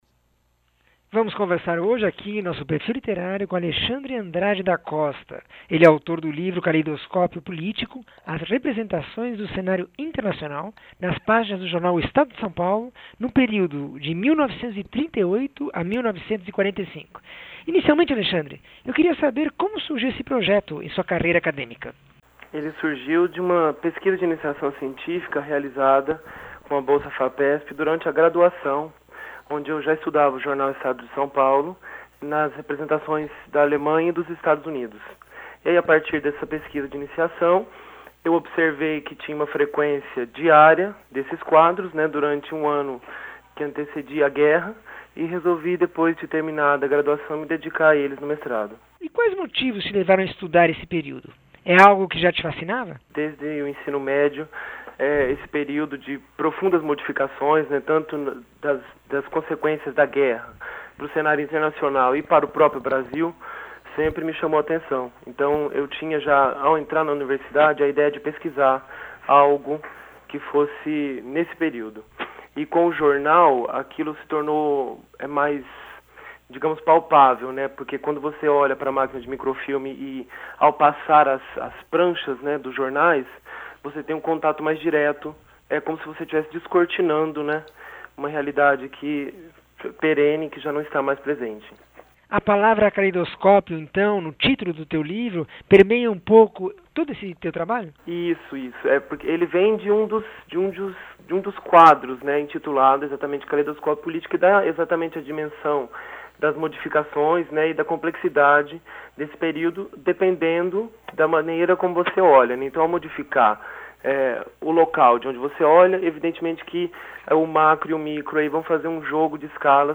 entrevista 1109